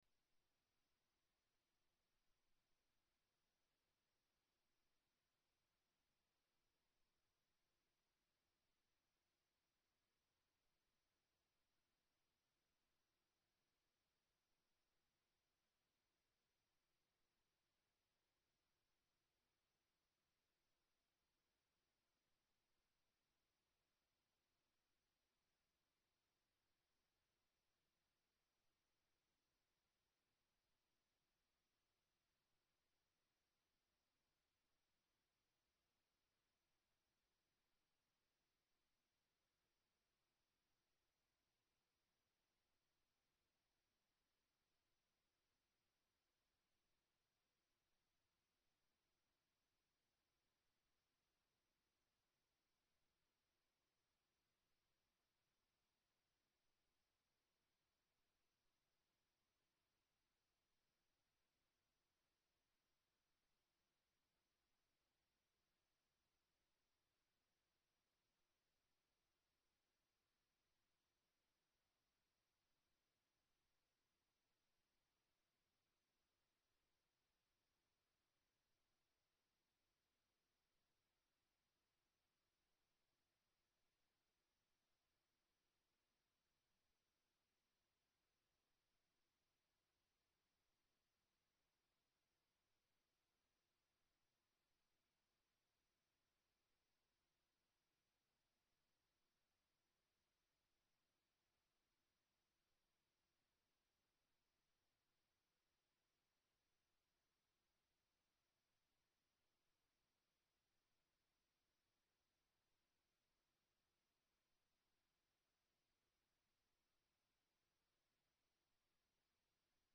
主日礼拝 「救いの醍醐味」